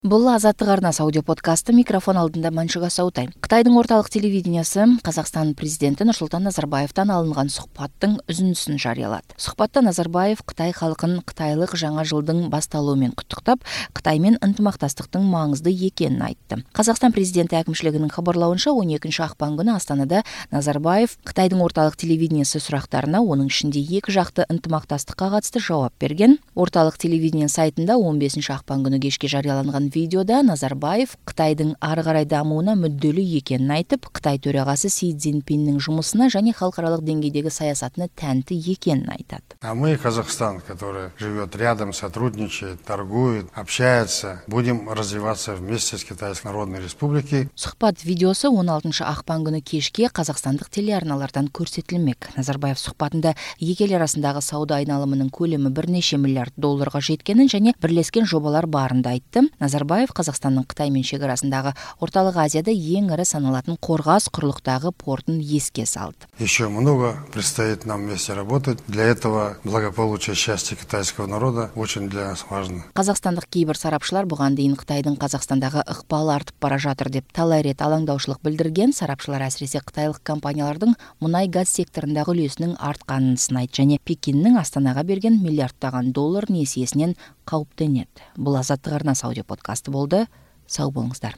Қытайдың Орталық телевидениесі (CCTV) Қазақстан президенті Нұрсұлтан Назарбаевтан алынған сұхбаттың үзіндісін жариялады.